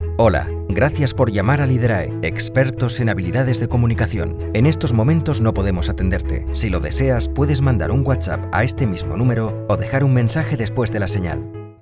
Commercial, Natural, Versatile, Reliable, Corporate
Telephony